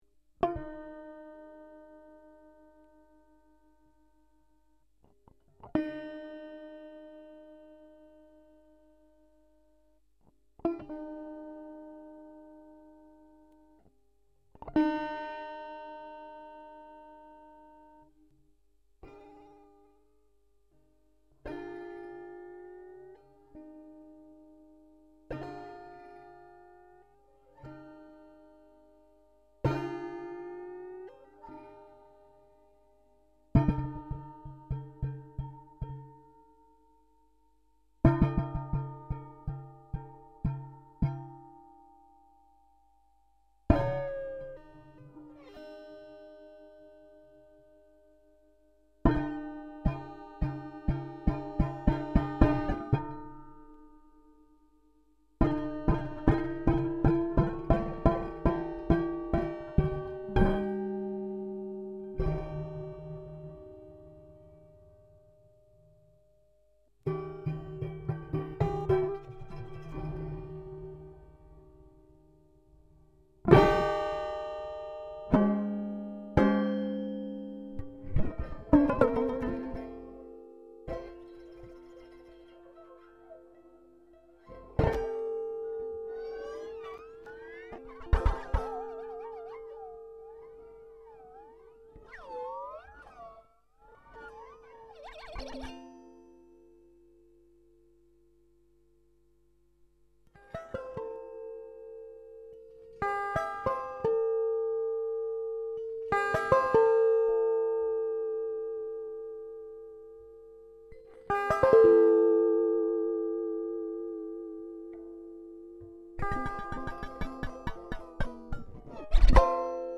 I experimented with preparing the guitar by inserting screwdrivers, pens and nail files between the strings.
Preparing the strings selected a range of tunings (like adding movable frets, like a sitar) that could be changed as it is played. The guitar was played by laying it flat on a table and using drumsticks, the whammy bar and plucking with the thumbs in the manner of an African mbira (thumb piano). The guitar was recorded from it’s pickups without processing, so the natural sound of the paraphenalia is missing and really displaces the expected sound of a guitar.
(4/1994 5:42, stereo)